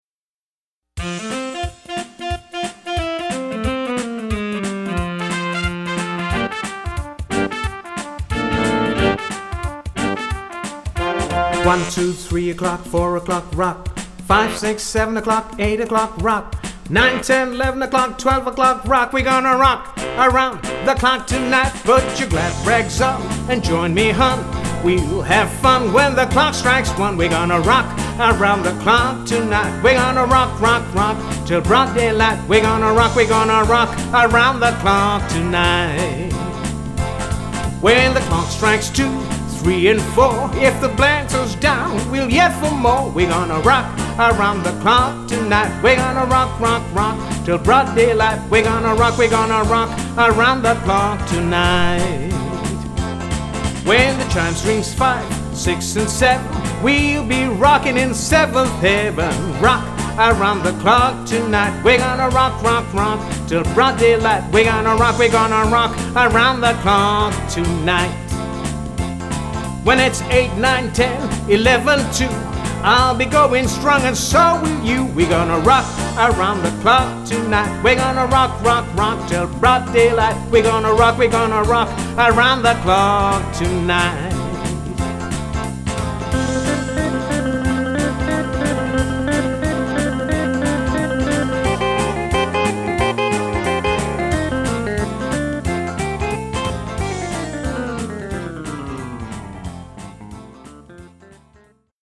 50’s & 60’s Golden Pop Oldies